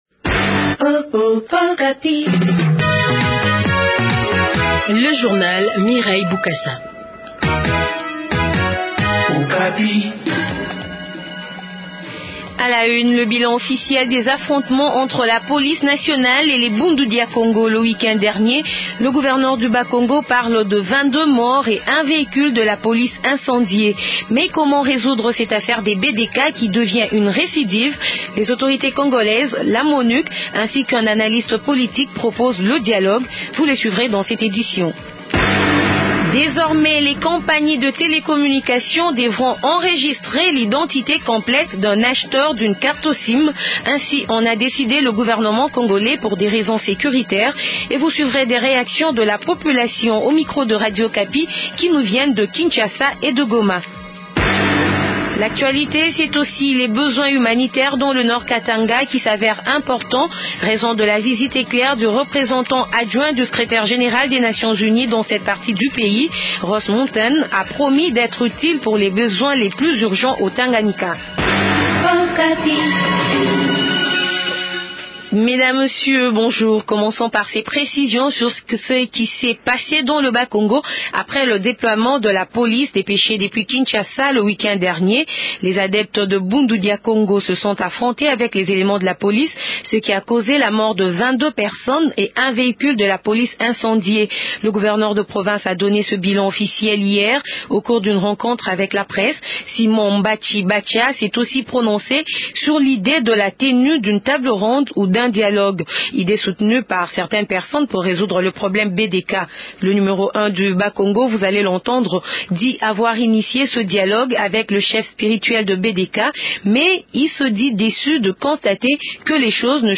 Journal Français Matin
Ainsi en a décidé le gouvernement congolais pour des raisons sécuritaires. Et vous suivrez des réactions de la population au micro de Radio Okapi qui nous viennent de Kinshasa et de Goma.